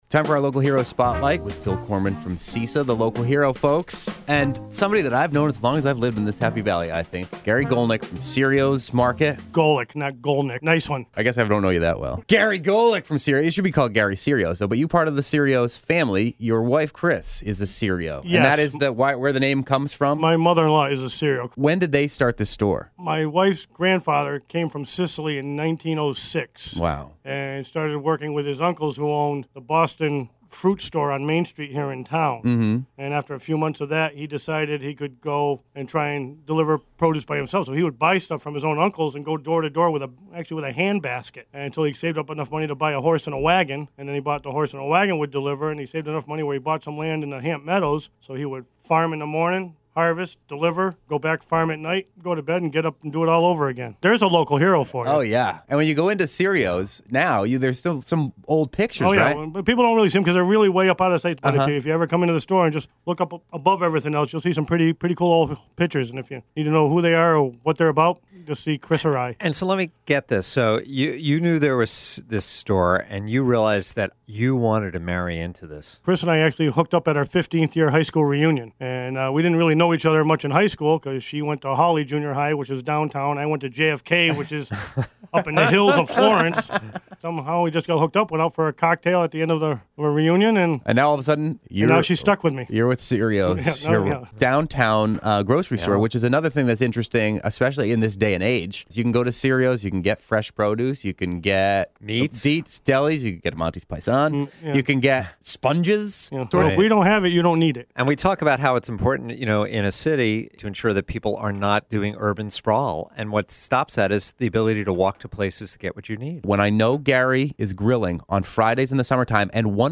Interview: Serio’s Market